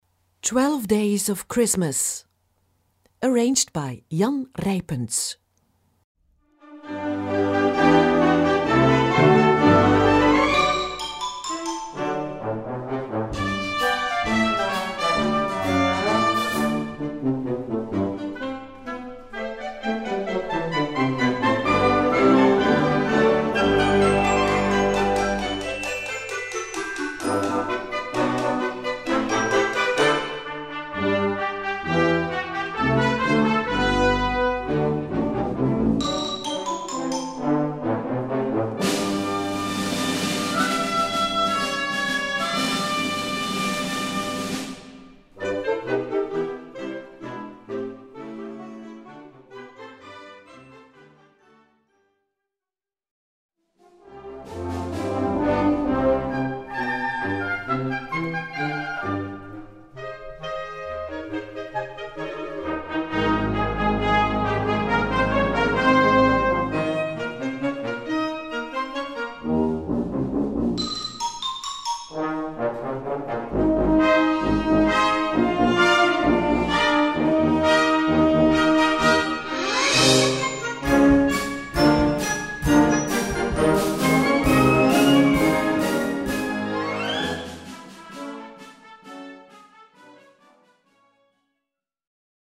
Gattung: Weihnachten
Besetzung: Blasorchester